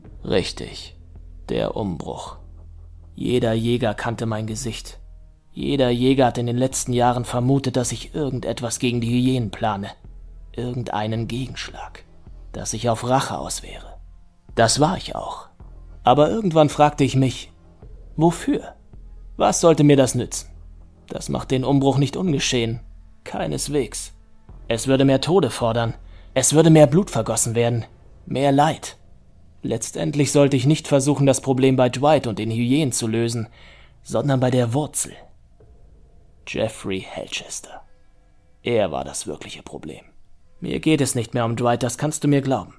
klare, sanfte und ausdrucksstarke Stimme mit Wiedererkennungswert / auch Trickstimme
Sprechprobe: Werbung (Muttersprache):